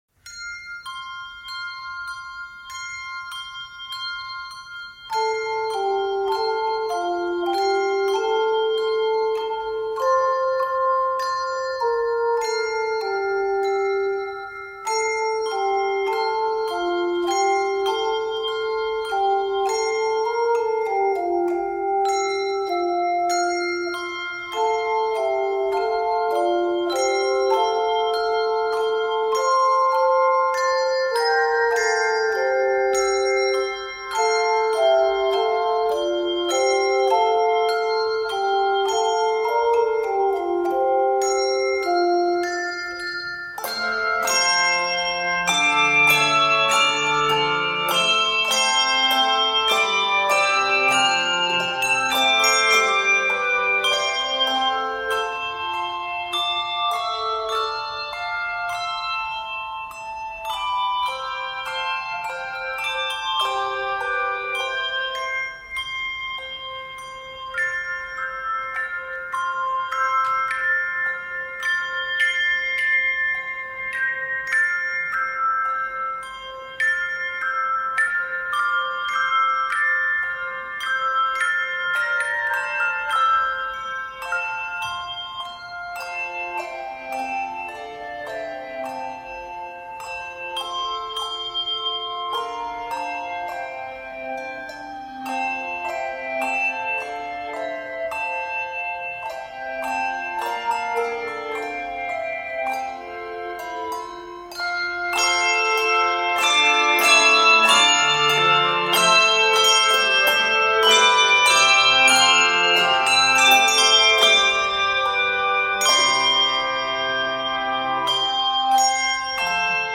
Key of F Major.